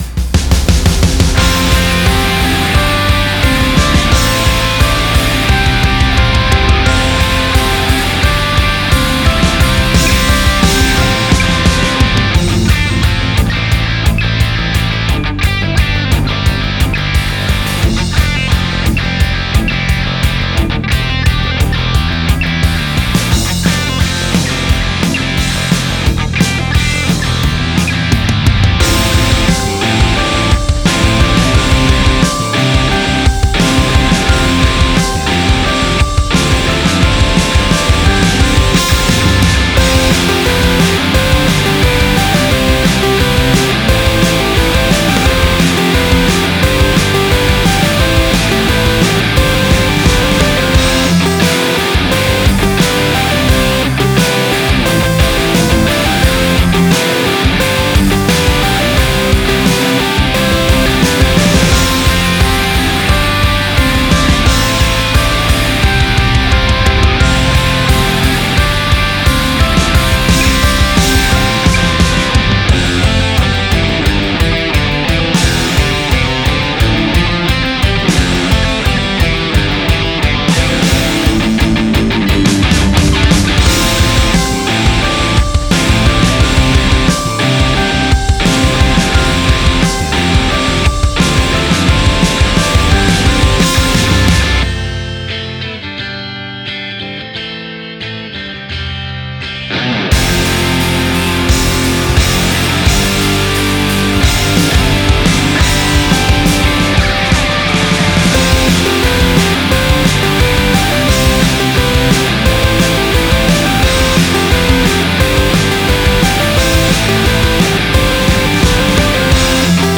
■OFF VOCAL